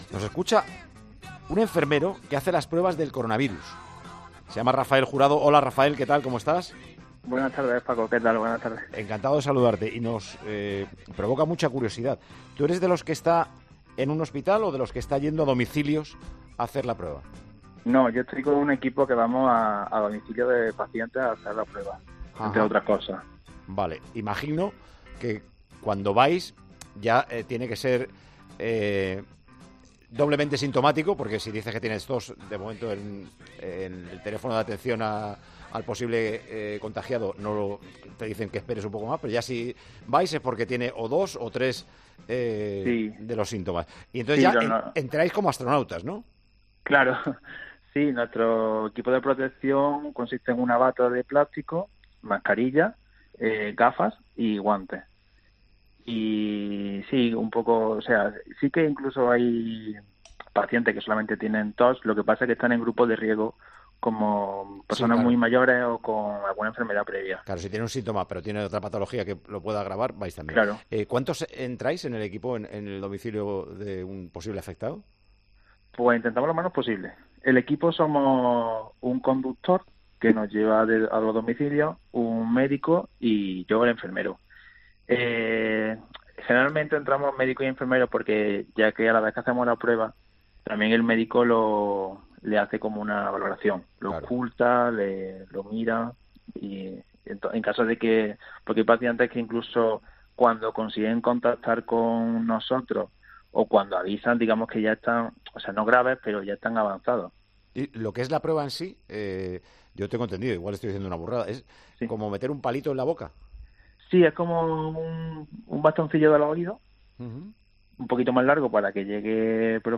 Un enfermero nos cuenta cómo están realizando los test de coronavirus
Con Paco González, Manolo Lama y Juanma Castaño